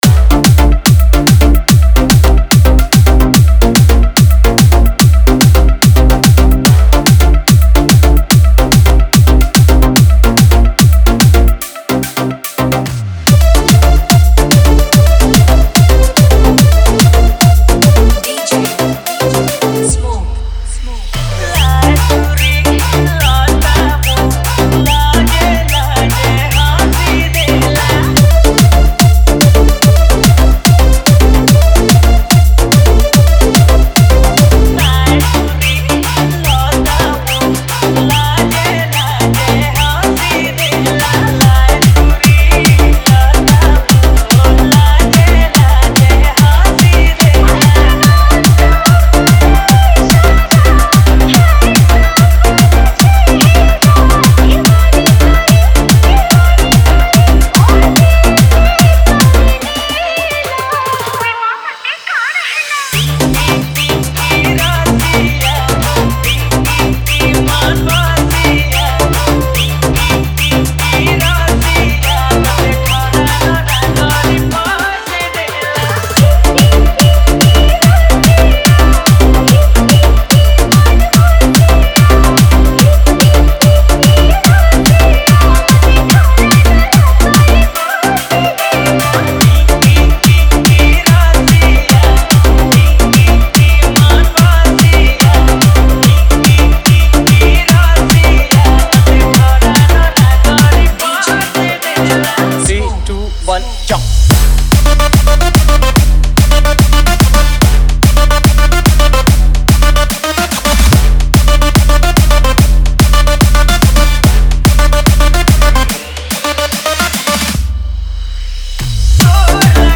Category: New Sambalpuri Folk Dj Songs 2022